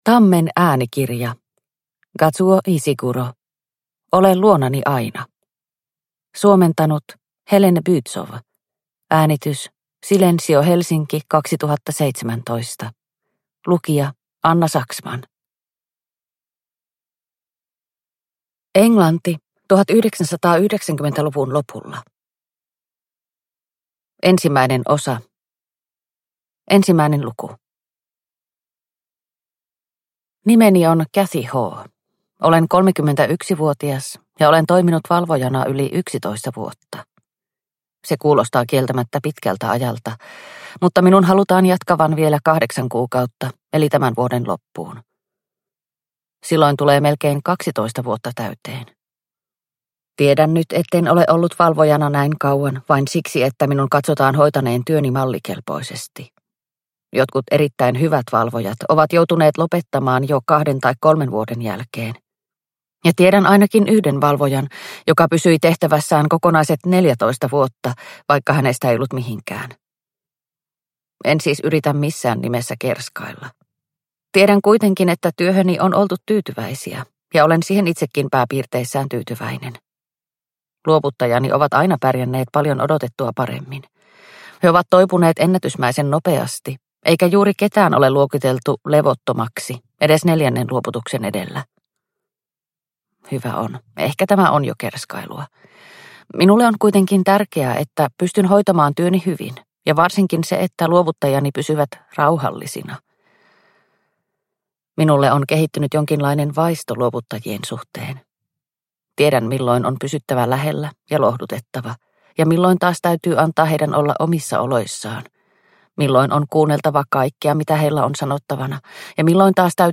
Ole luonani aina – Ljudbok – Laddas ner